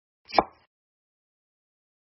Tiếng Cắt, Thái… bằng dao (một tiếng duy nhất)
Thể loại: Tiếng ăn uống
Description: Một nhát dao duy nhất—tiếng “cắt”, “chém”, “băm”, “gọt”, “xẻ”, “phập” vang lên gọn gàng, dứt khoát, sắc như xé đôi không gian.
tieng-cat-thai-bang-dao-mot-tieng-duy-nhat-www_tiengdong_com.mp3